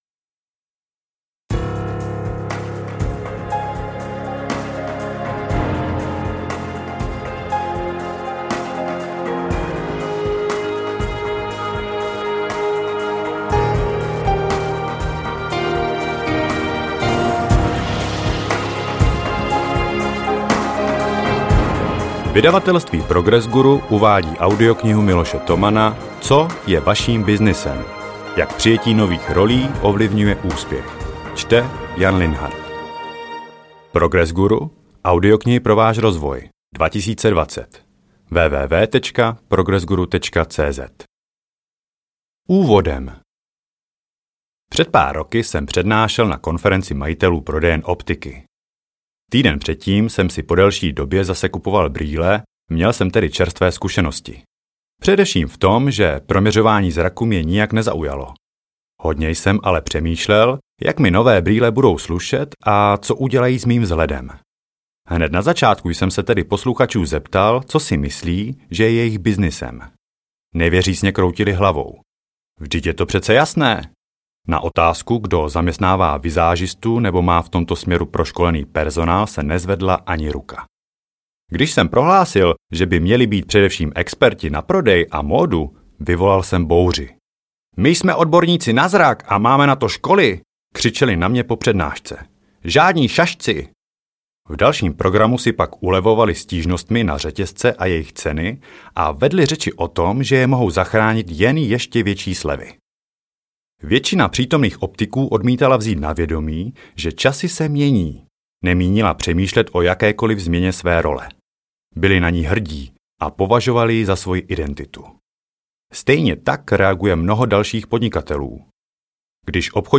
Co je vaším byznysem? audiokniha
Ukázka z knihy